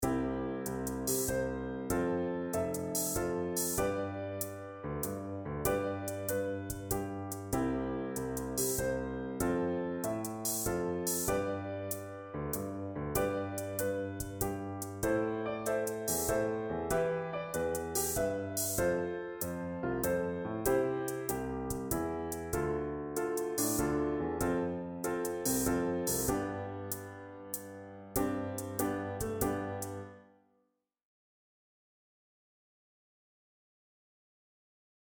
Now create a chord progression that focuses on the ii-chord (Dm) as a kind of tonic. This will work well as a verse progression (Example: Dm  Am7  G  Dm  Am7  G |F  Am  Dm  C/E  F  G) [LISTEN]
ii_chord_minor2.mp3